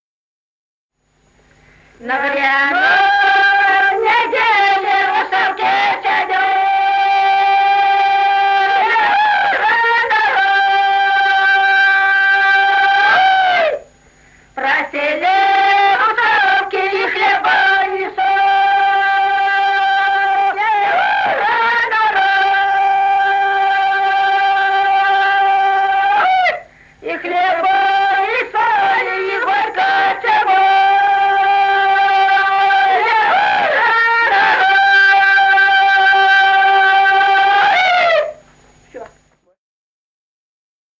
Народные песни Стародубского района «На гряной неделе», гряная.
1959 г., с. Курковичи.